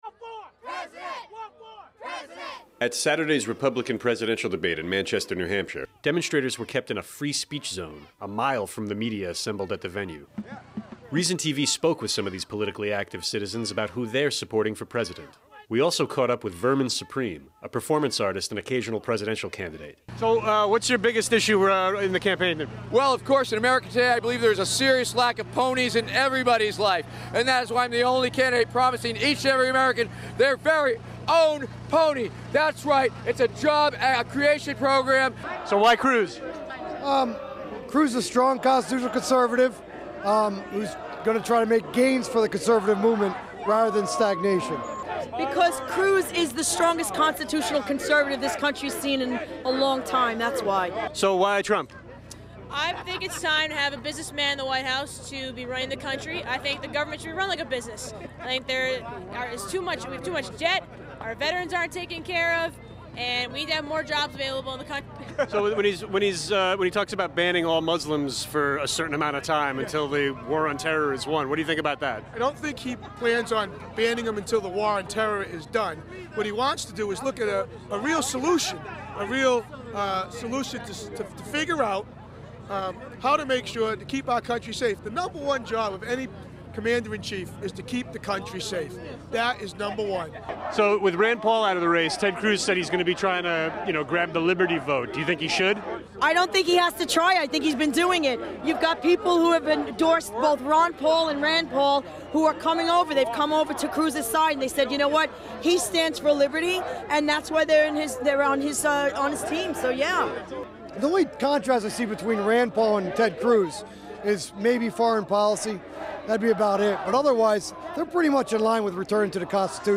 We visited the demonstrators confined to the "Free Speech Zone" a mile away
At Saturday's Republican presidential debate in Manchester, New Hampshire, demonstrators were kept in a "free speech zone" a mile from the media assembled at the venue. Reason TV spoke with some of these politically active citizens about who they are supporting for president.